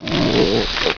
attacktear.wav